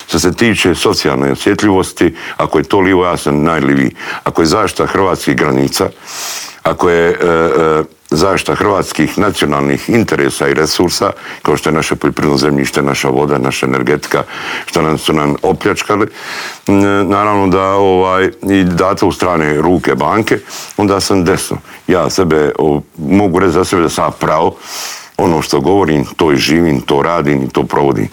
Njihov kandidat Miro Bulj bio je gost u Intervjuu tjedna Media servisa i otkrio zašto se kandidirao i po čemu se izdvaja od ostalih kandidata.
Miro Bulj izjavio je u studiju Media servisa da se kandidirao za predsjednika države jer mu je ‘‘puna kapa‘‘ gledati kako Hrvatska demografski tone i kako se vladajući odnose prema Hrvatskoj vojsci.